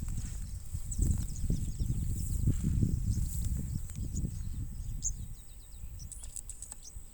Correndera Pipit (Anthus correndera)
Class: Aves
Detailed location: Dique Río Hondo
Condition: Wild
Certainty: Recorded vocal